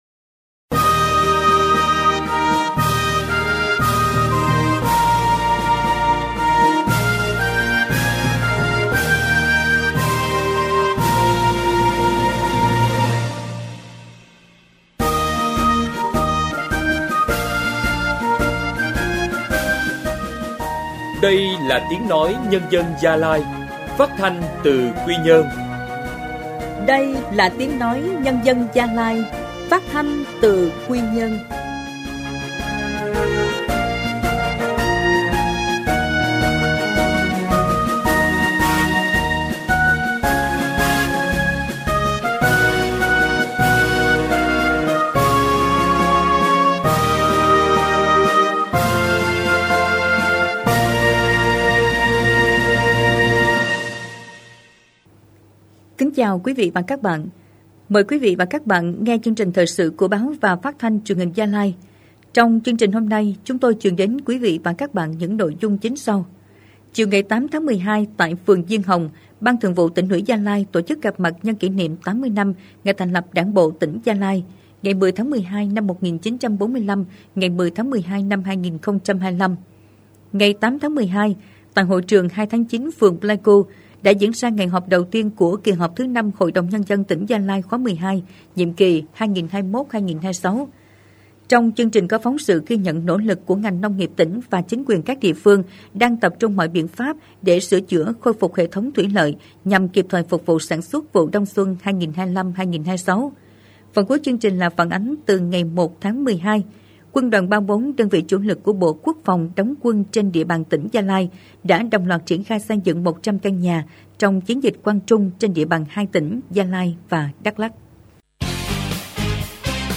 Thời sự phát thanh tối